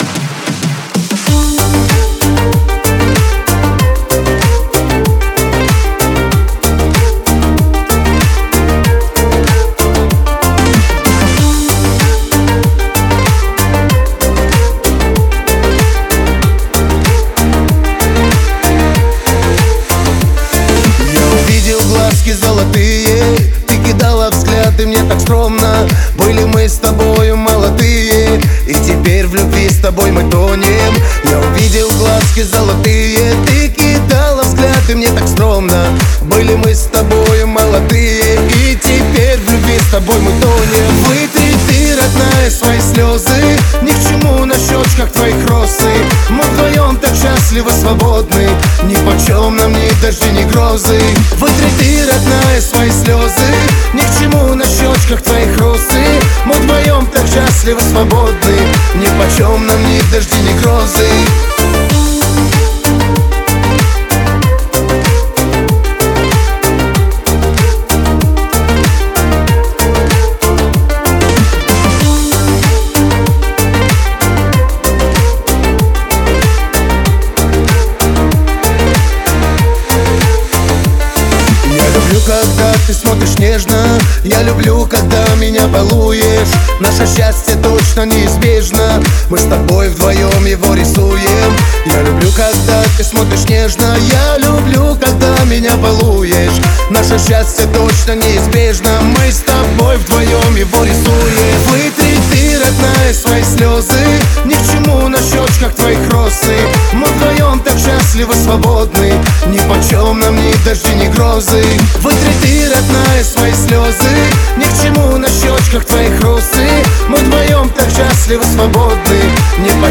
это проникновенная композиция в жанре поп-музыки
мощный голос